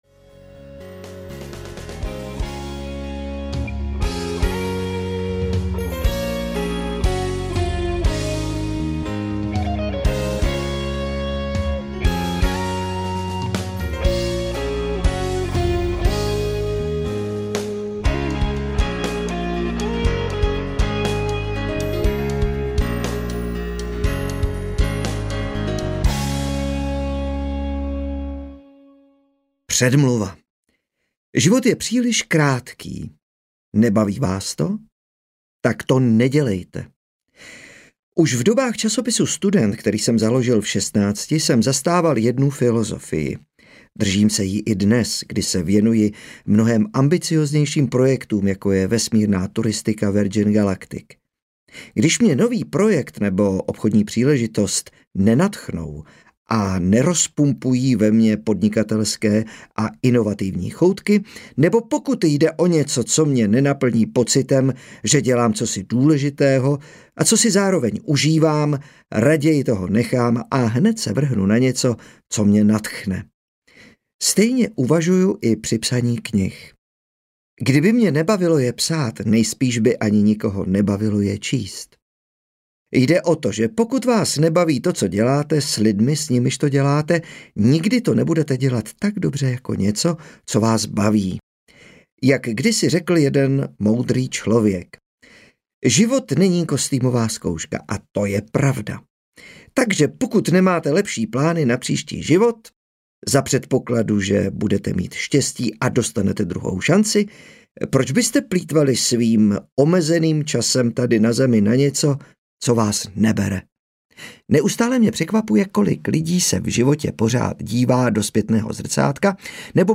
Tak to dělá Virgin audiokniha
Ukázka z knihy